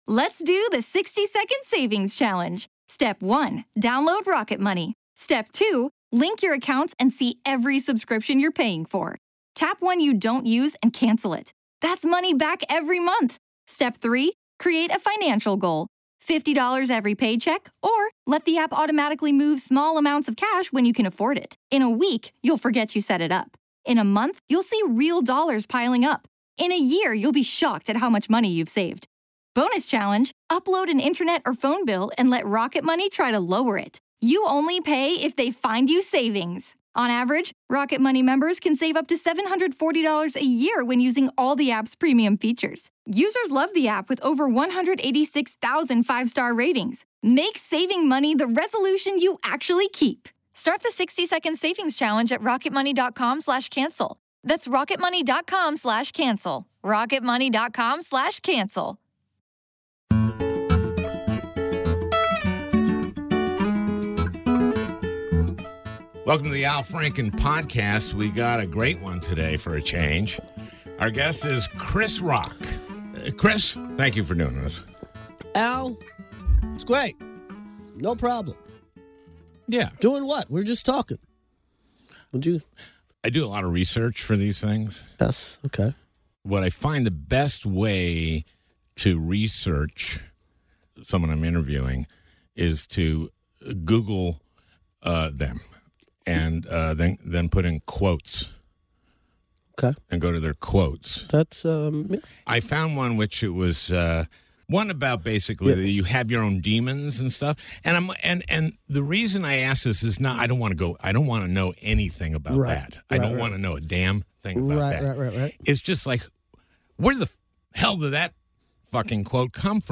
A Conversation with Chris Rock